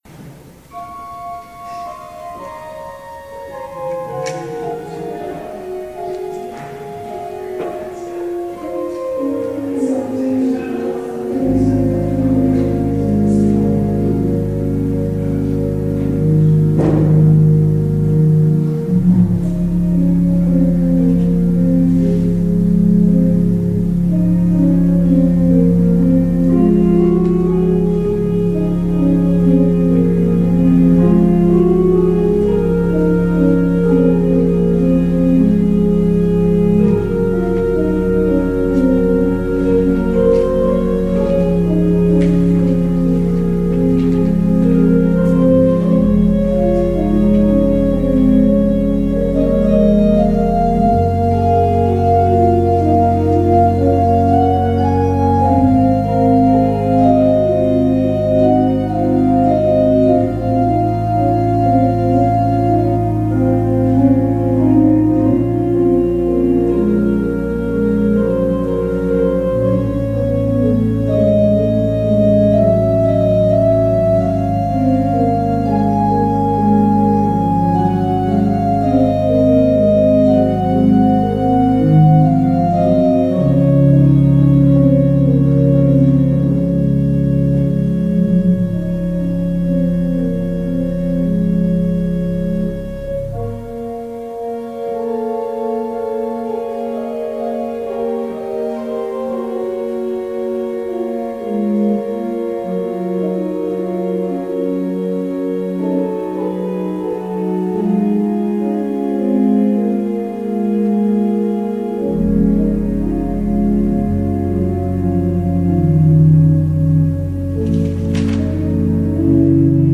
Audio recording of the 7pm hybrid/streamed service